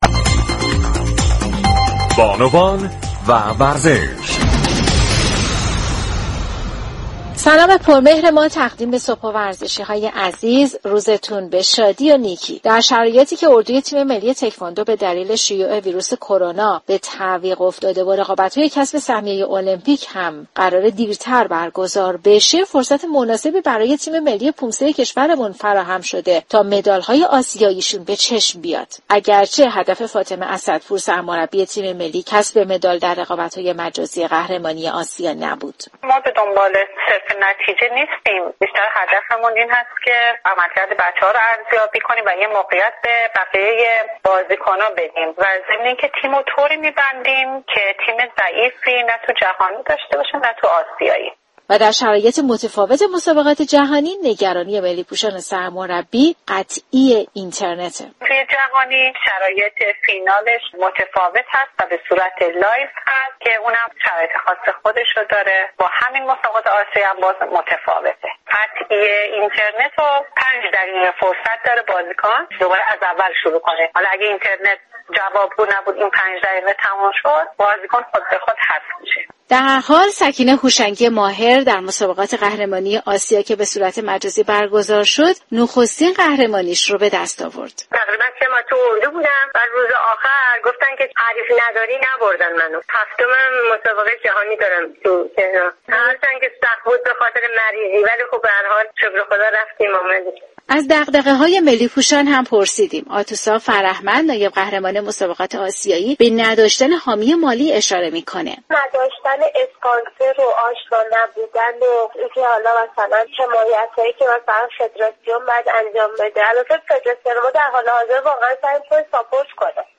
گزارشگر رادیو ورزش در گفتگو با ملی پوشان پومسه دغدغه های آنها را جویا شد.